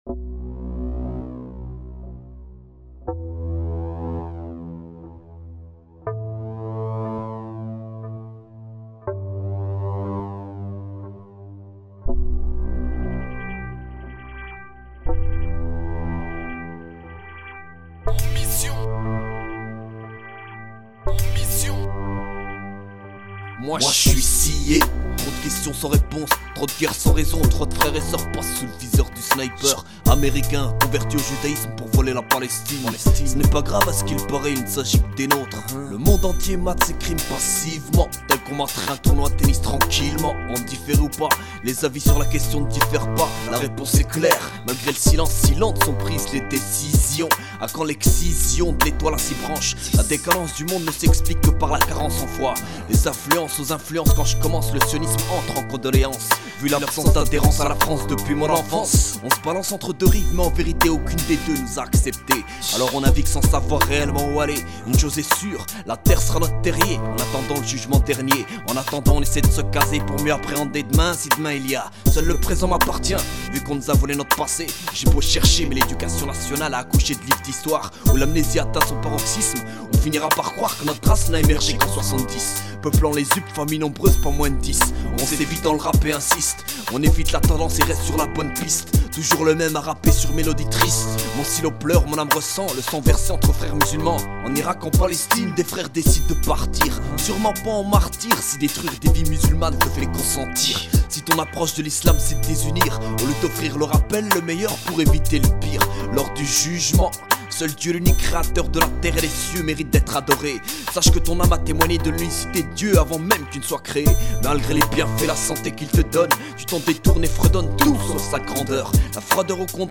FLERS 61100 RAP INDEPENDANT HIPHOP INDEPENDANT HARDCORE